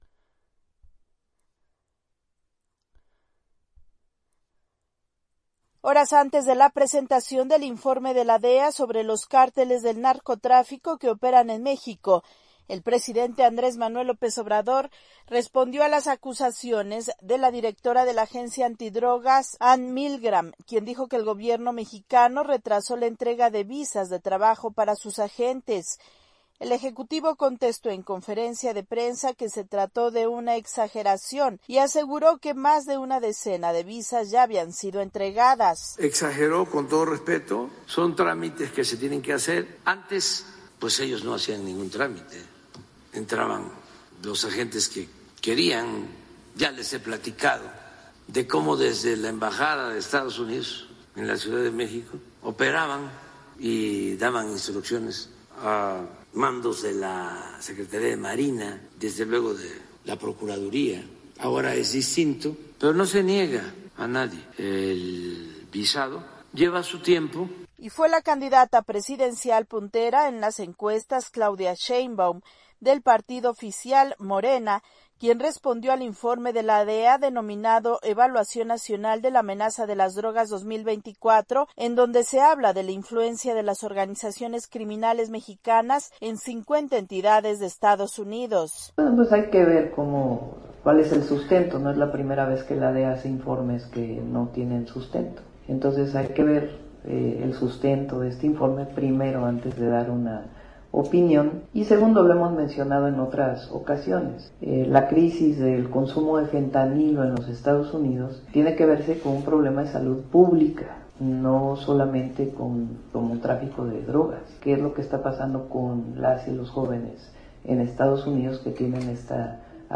AudioNoticias
desde Ciudad de México